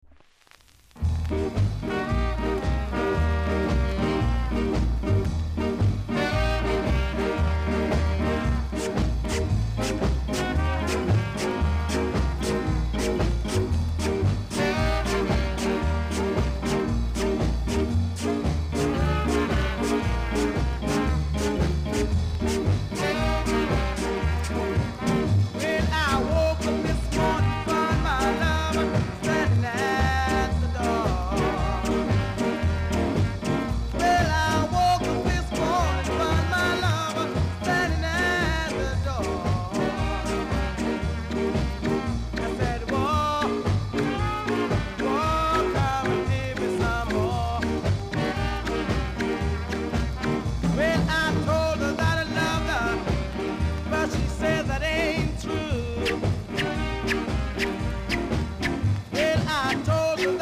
※全体的にチリ、ジリノイズがあります。
コメント NICE SKA!!